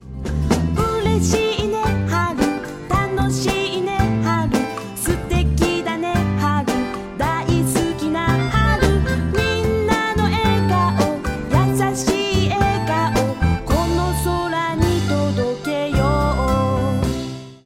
北海道の帯広に当時できたばかりのスタジオでレコーディング